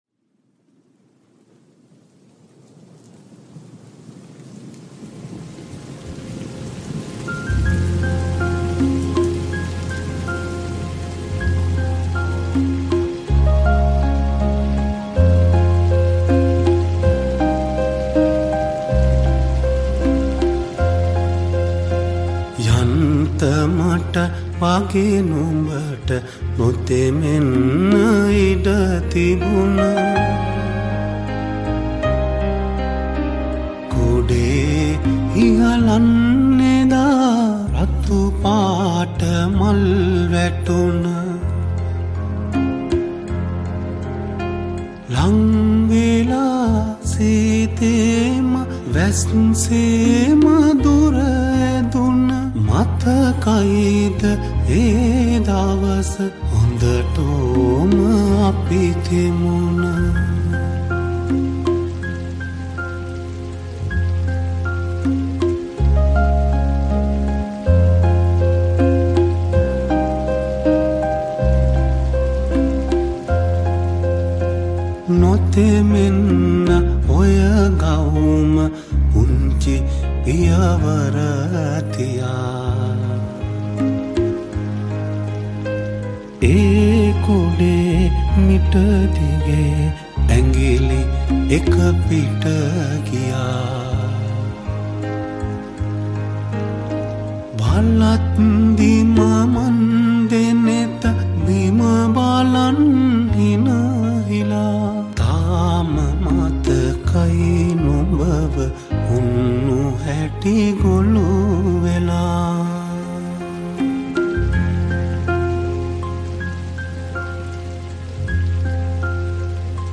Vocals